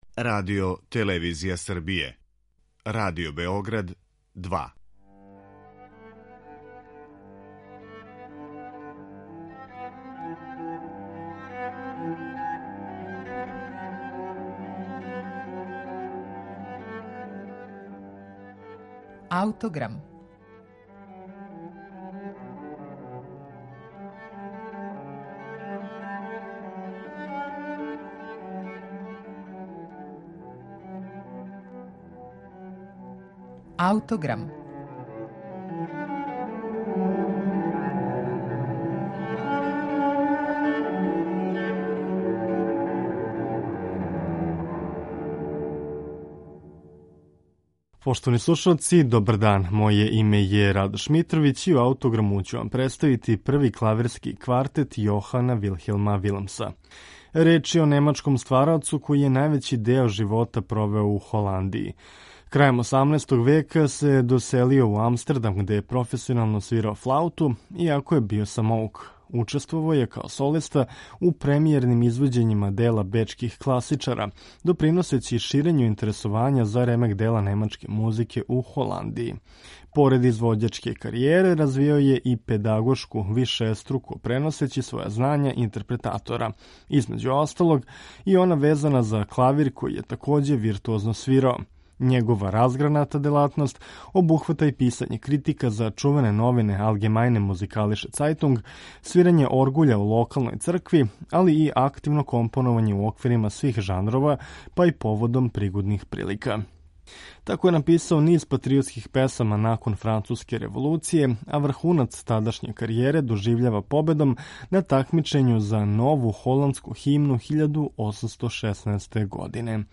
Ми ћемо слушати његов клавирски квартет, који показују богатство израза овог заборављеног уметника. Дело слушамо у интерпретацији квартета Валентин.